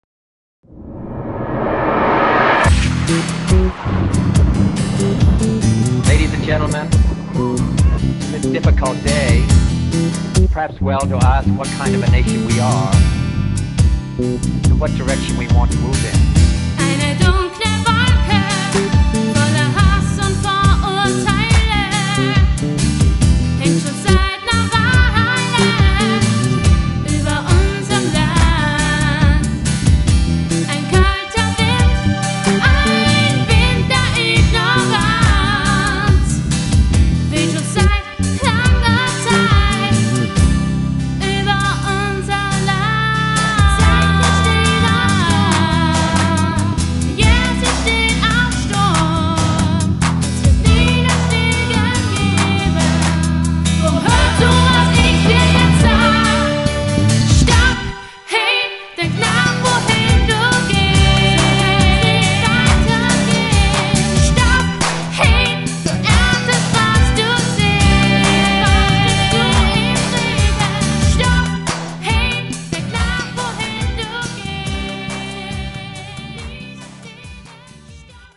bass
drums/percussion